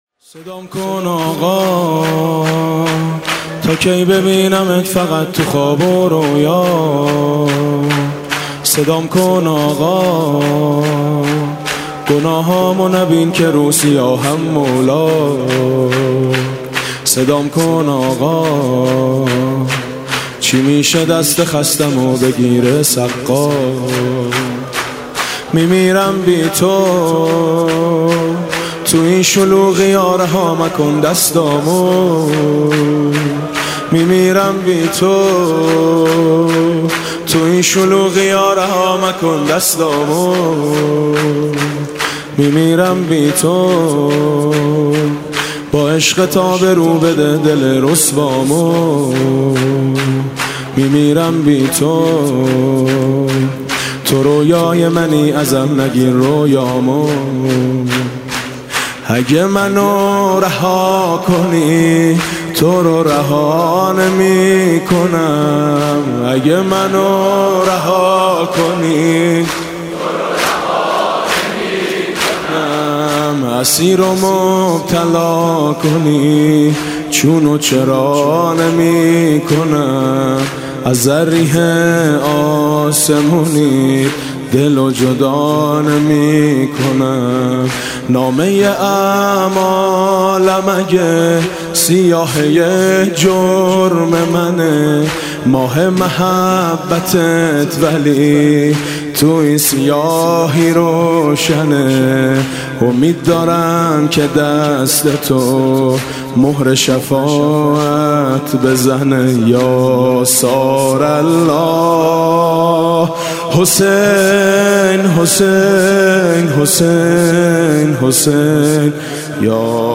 (نوحه)